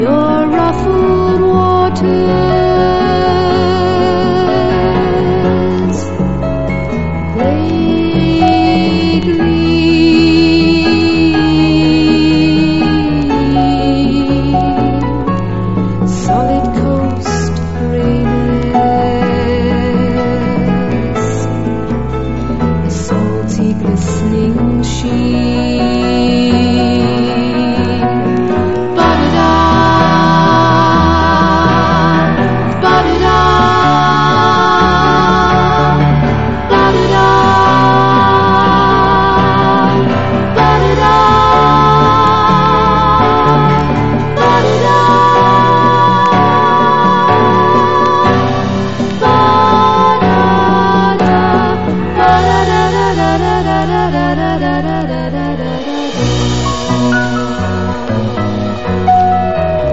ROCK / 70'S / PROGRESSIVE ROCK / FOLK / TRAD / PSYCHEDELIC
アシッド・フォーク～サイケ好きも必聴の幻想的エクスペリメンタル・フォーキー・グルーヴ！